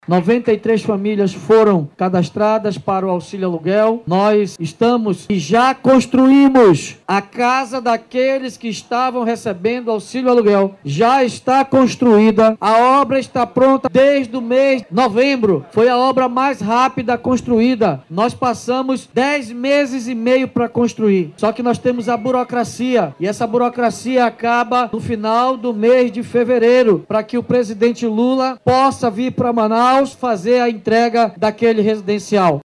O prefeito David Almeida informou que 93 famílias receberam auxílio-aluguel e que as moradias já estão prontas.
SONORA-2-DAVID.mp3